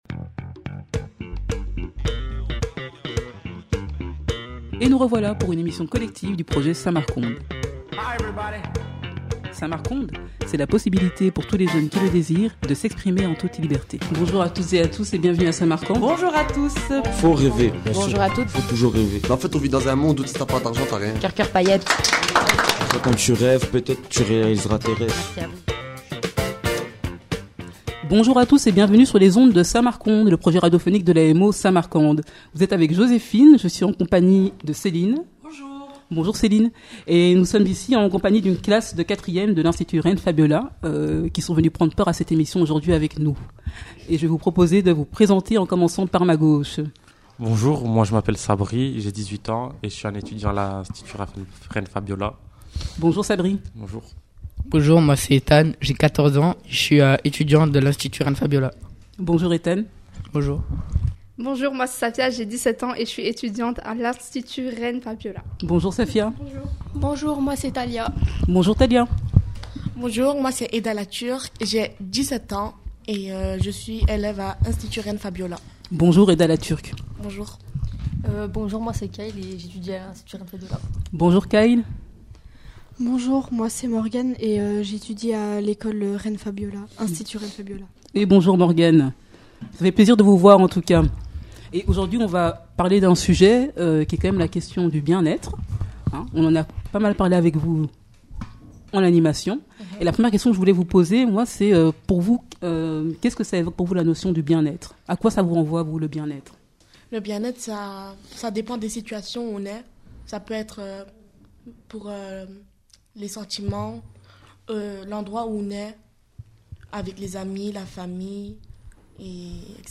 Le projet radio Samarc'ondes, ce sont des témoignages de jeunes que nous récoltons chaque semaine depuis maintenant plus de 10 ans, avec une attention particulière portée aux jeunes socialement invisibilisés à qui l'on tend trop rarement le micro.